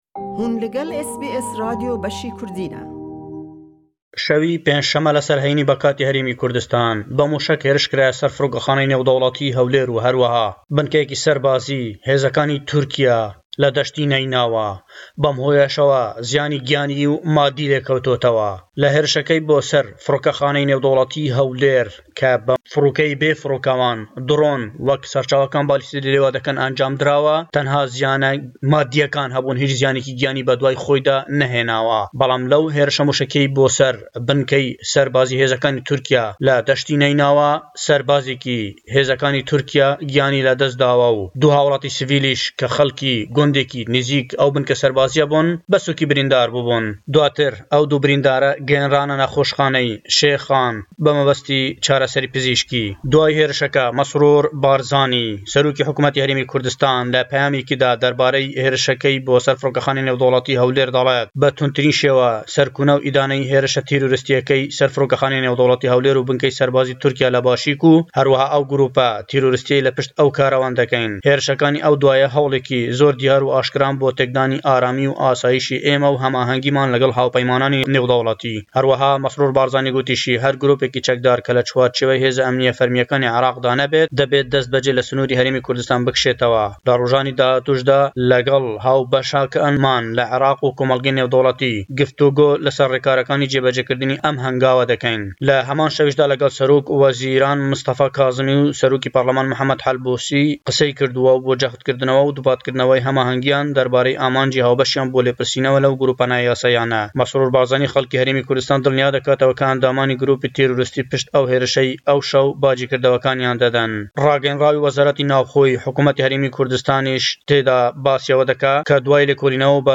Raportêkî taybet le Hewlêre we sebaret be teqînewe le Firrokexaney Hewlêr, giyan le sest danî hawillatiyan sîvîl le gûndêkî Başûrî Kurdistan.